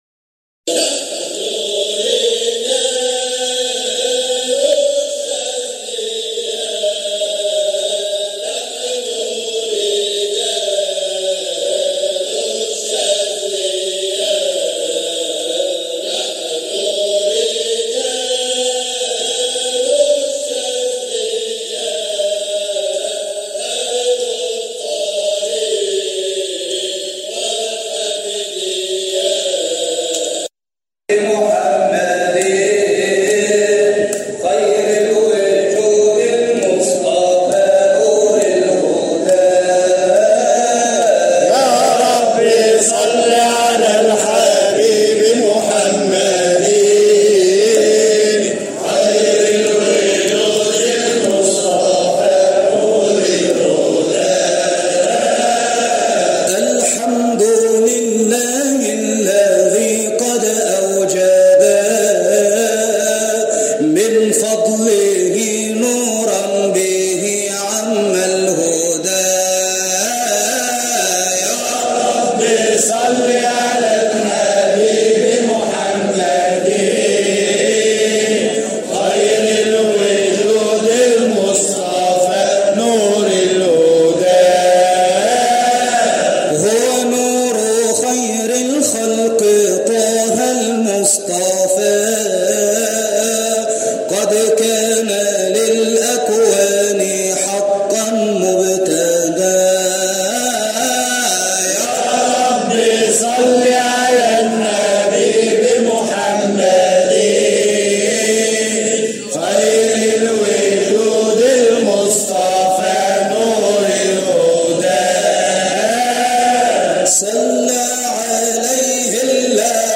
مقاطع من احتفالات ابناء الطريقة الحامدية الشاذلية بمناسباتهم